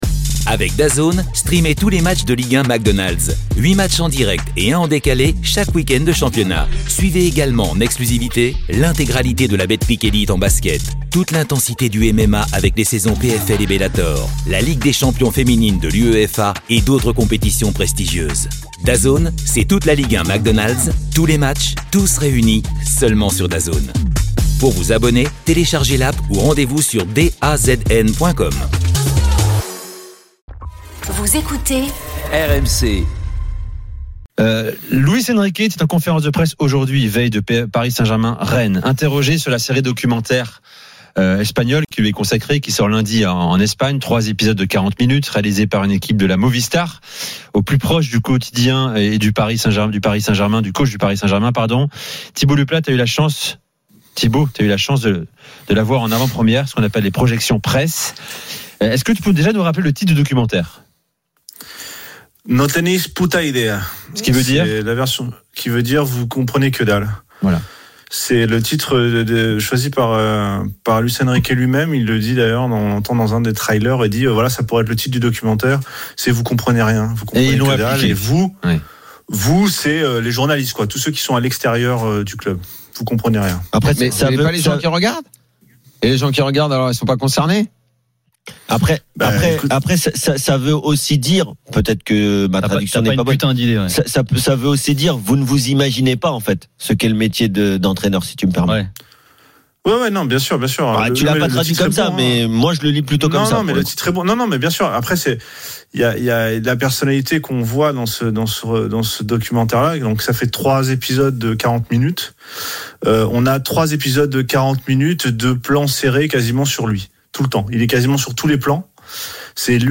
Chaque jour, écoutez le Best-of de l'Afterfoot, sur RMC la radio du Sport !
Les rencontres se prolongent tous les soirs avec Gilbert Brisbois, Daniel Riolo et Florent Gautreau avec les réactions des joueurs et entraîneurs, les conférences de presse d’après-match et les débats animés entre supporters, experts de l’After et auditeurs RMC.